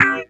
Index of /90_sSampleCDs/Zero-G - Total Drum Bass/Instruments - 2/track39 (Guitars)
09 LambChop E.wav